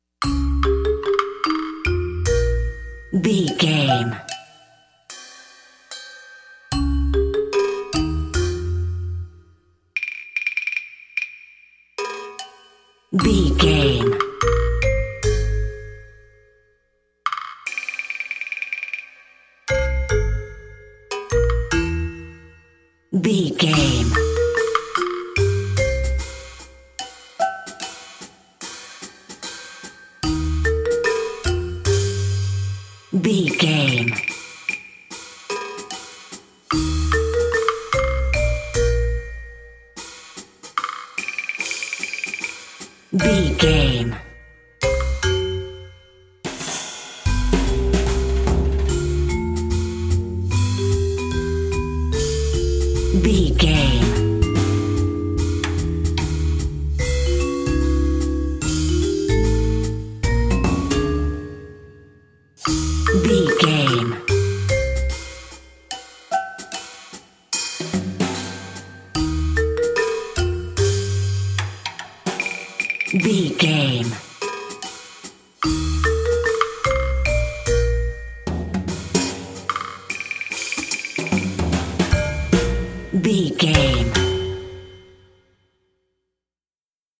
Ionian/Major
B♭
drums
percussion
double bass
silly
circus
goofy
comical
cheerful
perky
Light hearted
quirky